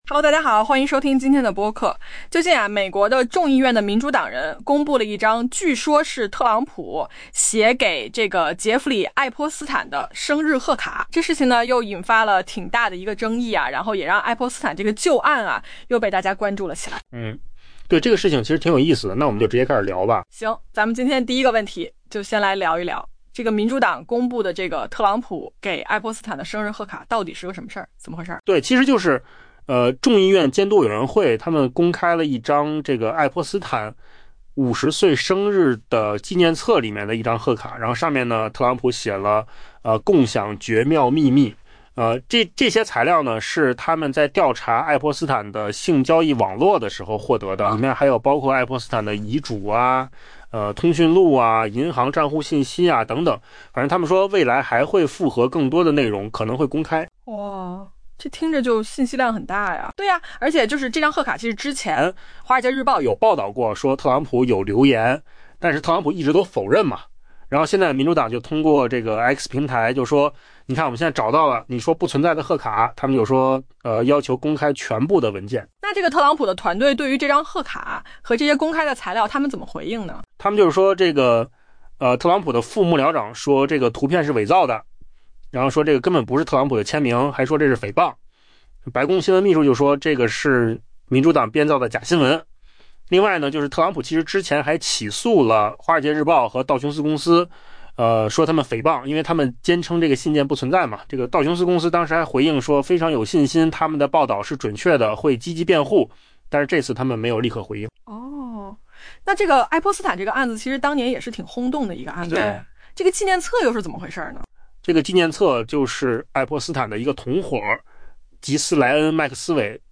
AI播客：换个方式听新闻